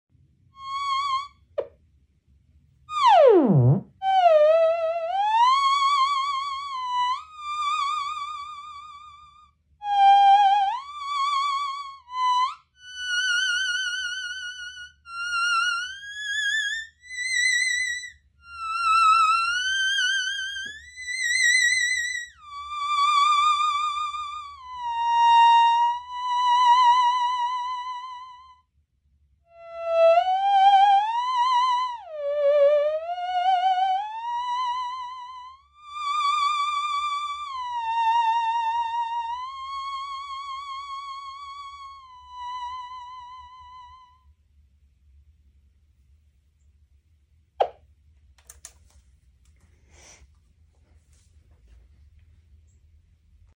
theremin